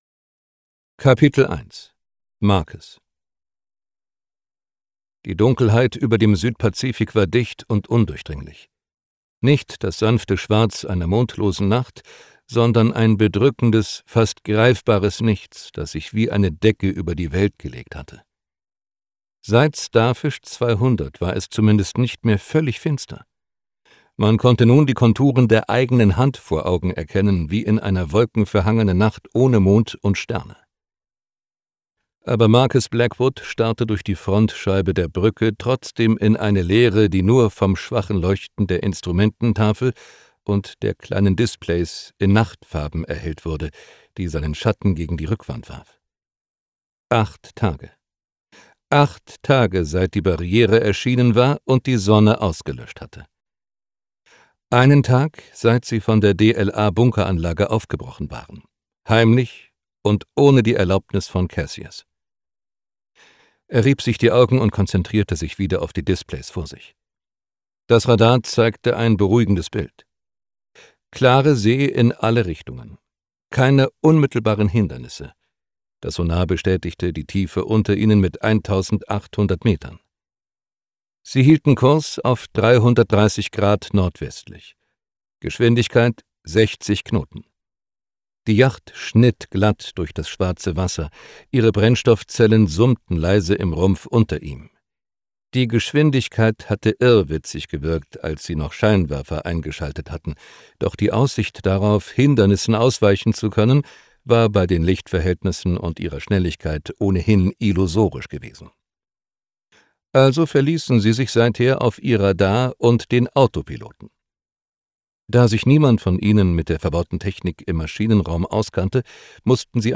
Gekürzt Autorisierte, d.h. von Autor:innen und / oder Verlagen freigegebene, bearbeitete Fassung.
Hörbuchcover von Die letzte Arche 3